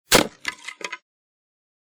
crack_door.ogg